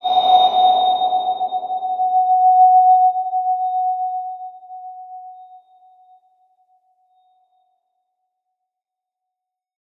X_BasicBells-F#3-pp.wav